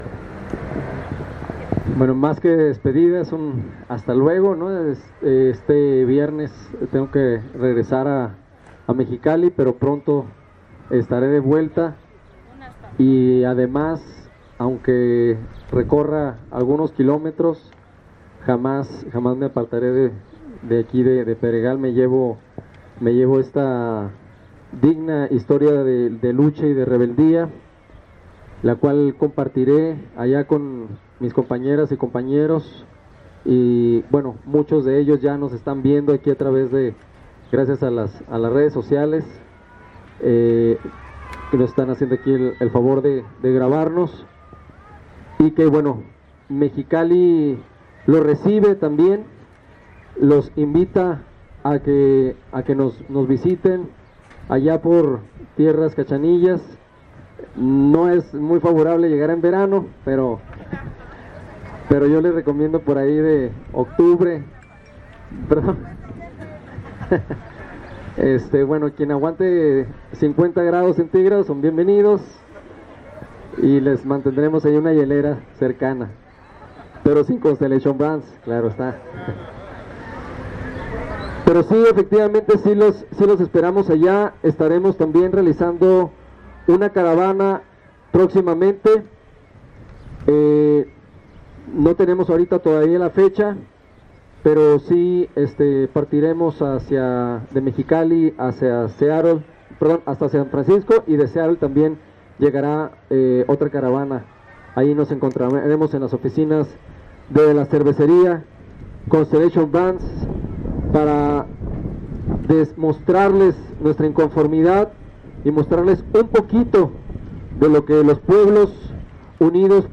Below we leave you with some photos and audios from the event.